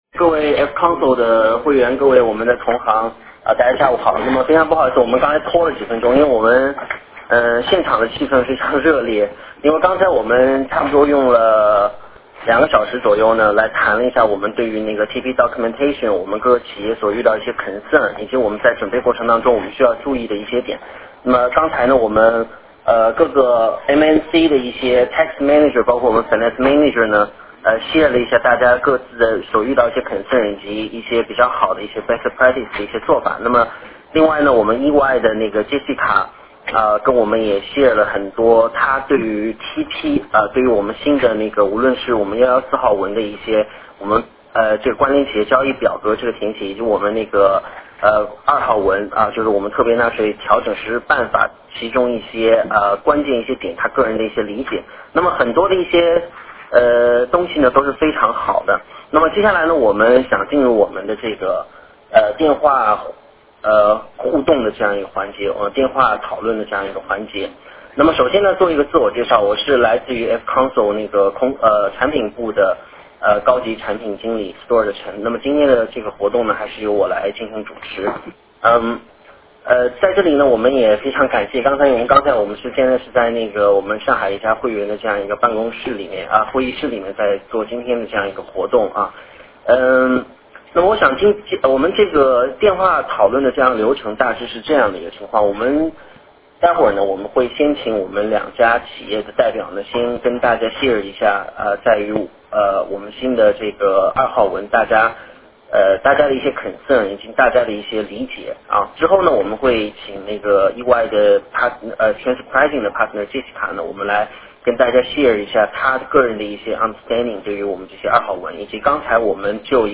电话会议
会前案例+线下讨论+线上交流“海陆空”立体会议